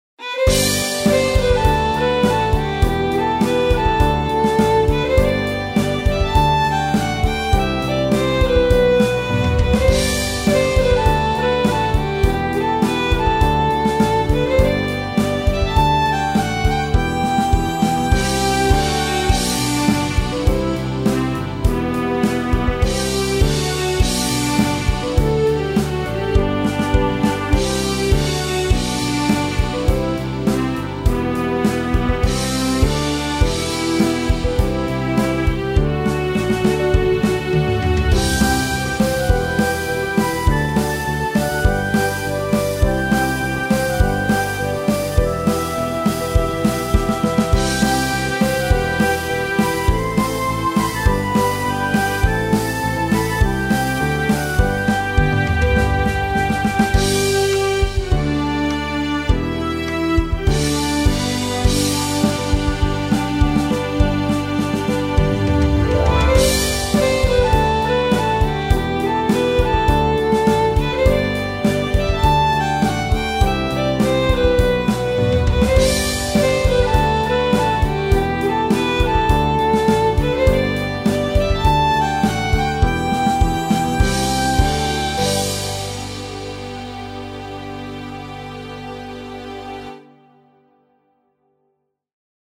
BGM バイオリン ストリングス フルート ポジティブ わくわく 春 秋 前向き 明るい 楽しい 笑顔 桜